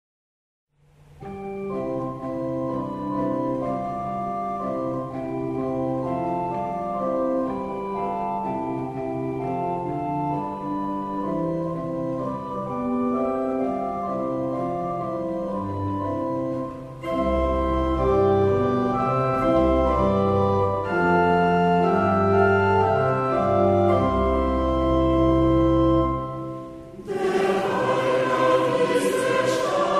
Genre-Stil-Form: Kirchenlied ; geistlich
Chorgattung: SAH  (3 gemischter Chor Stimmen )
Tonart(en): C-Dur